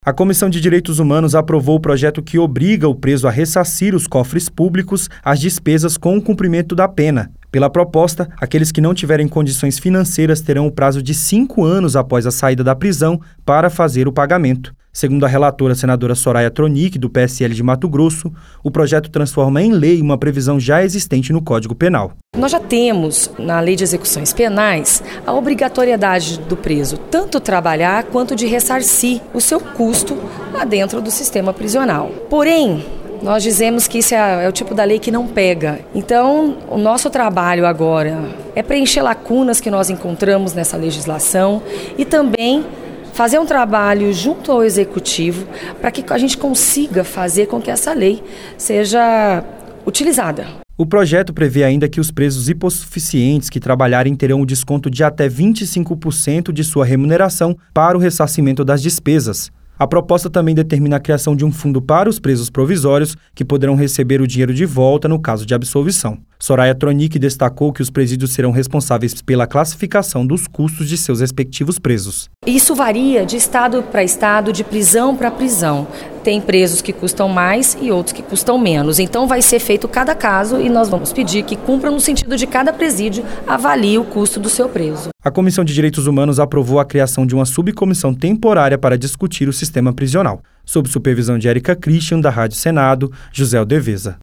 Ouça mais detalhes no áudio do repórter da Rádio Senado